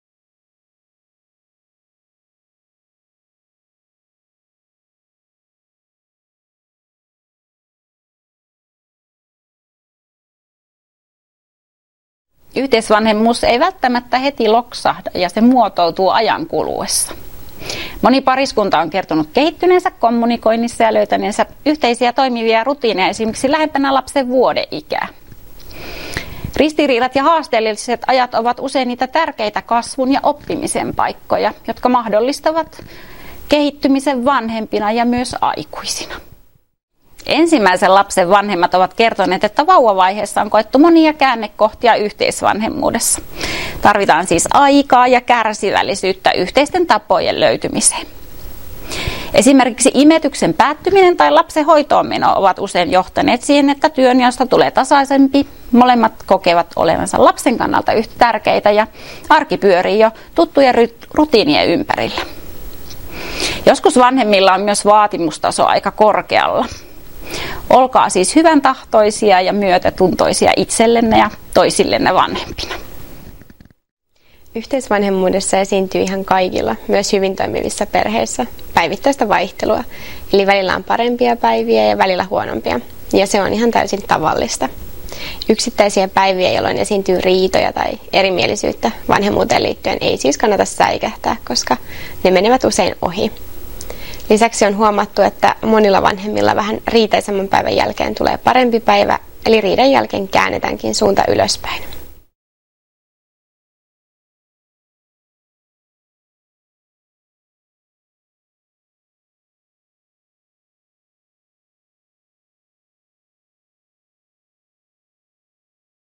Asiantuntijoiden haastattelu 3 — Moniviestin